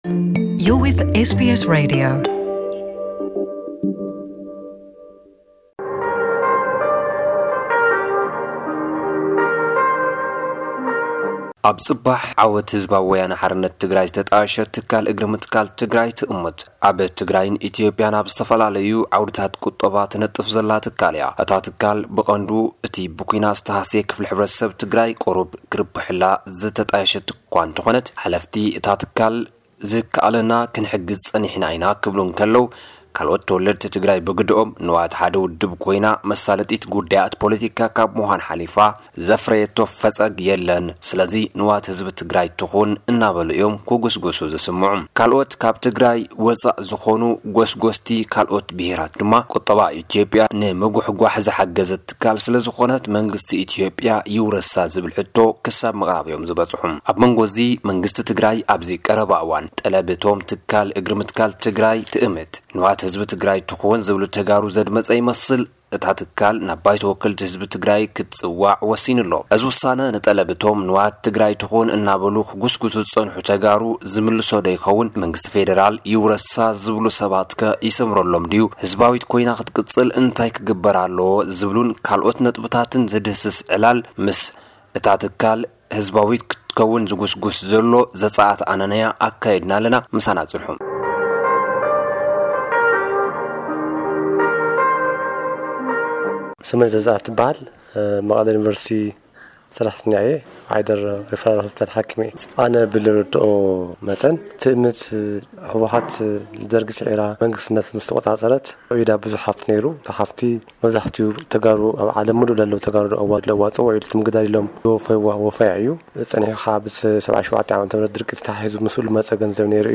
ቃለ መጠይቕ